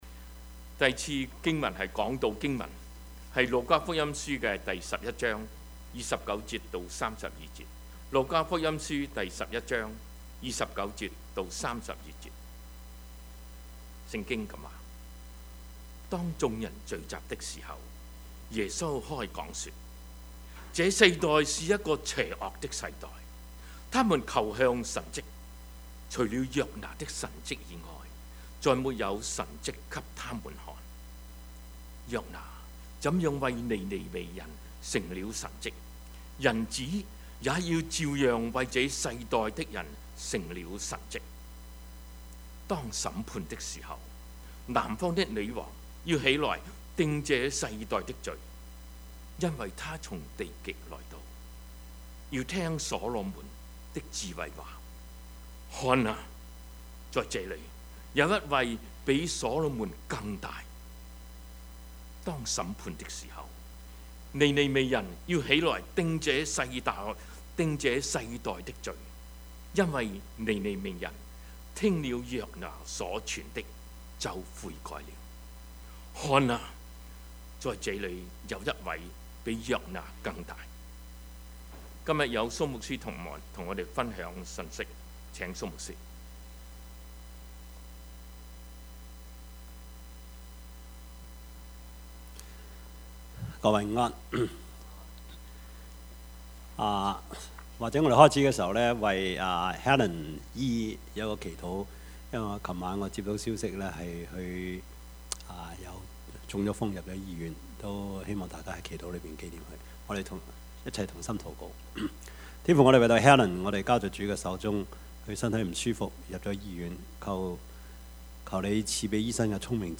Service Type: 主日崇拜
Topics: 主日證道 « 不是修補, 乃是重建 牛皮燈籠 »